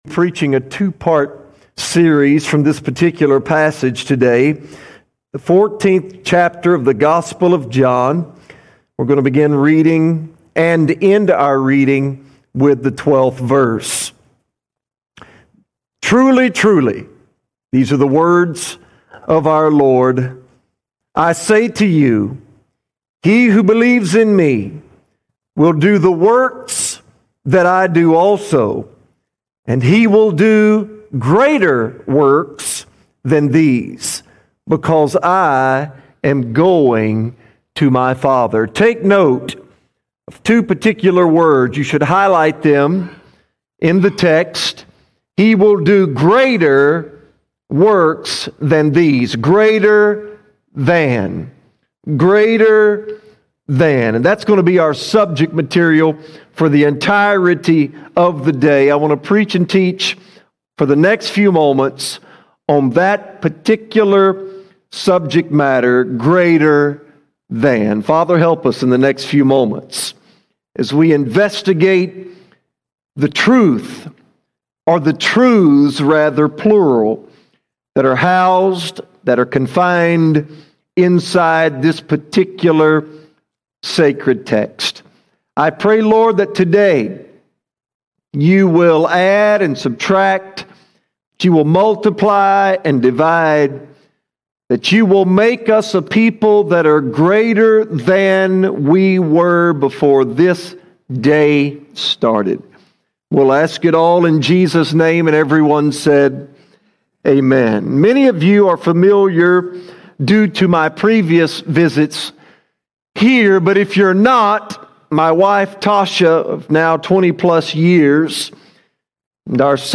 31 March 2025 Series: Guest Speakers All Sermons Greater Than> Part 1 Greater Than> Part 1 Sometimes Jesus adds to our lives, sometimes He subtracts.